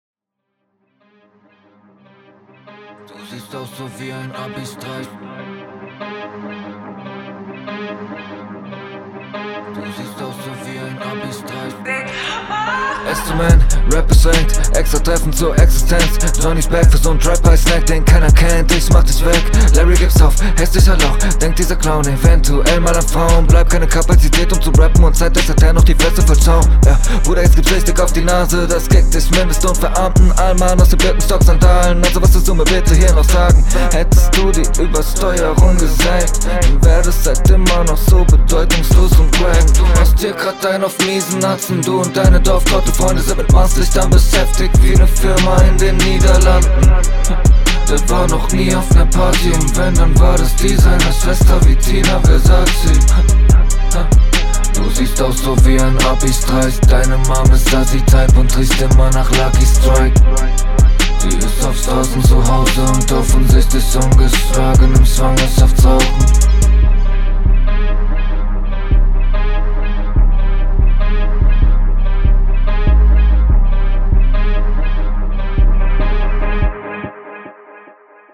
Driveby Runde